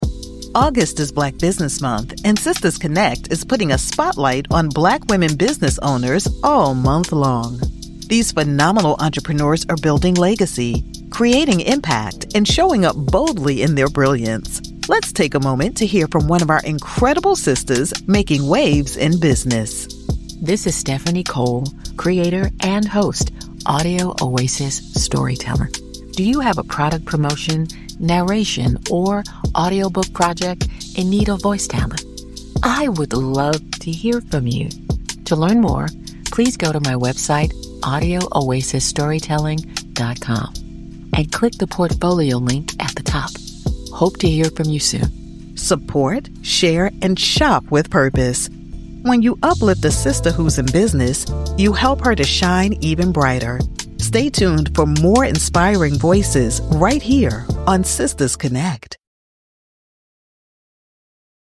Internet Radio Ad
Middle Aged